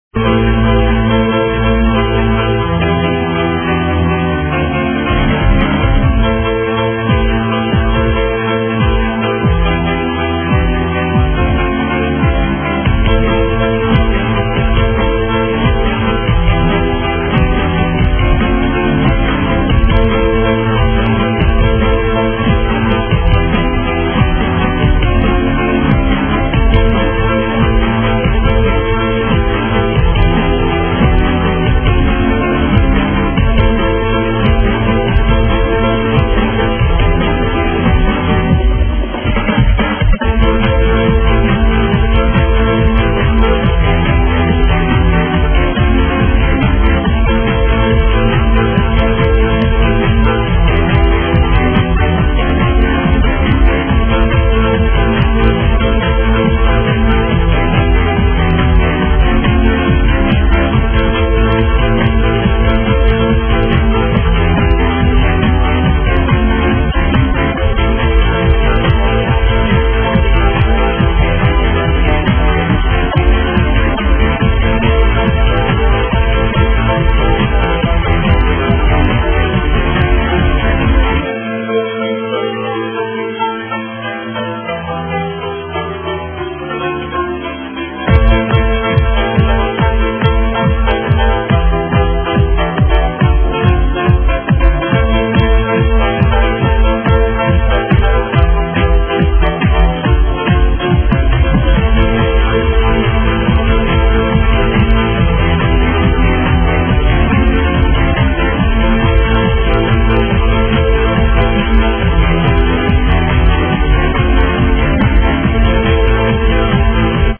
THIS IS THE PIANO SONG THAT I NEED AN ID FOR